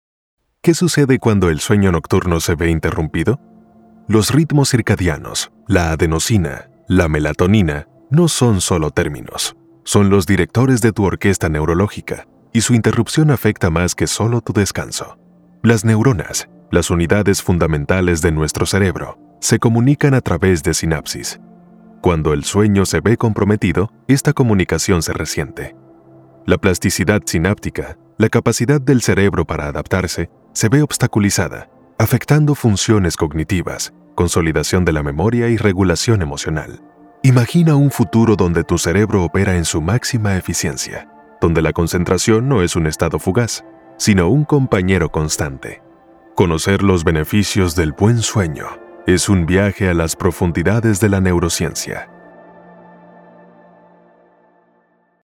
Male
Dominican Spanish (Native) Latin English (Accent) Neutral Latam Spanish (Native)
Energic.mp3
Acoustically treated studio.